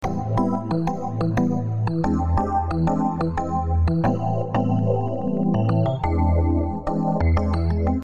Tag: 120 bpm Jazz Loops Piano Loops 1.35 MB wav Key : Unknown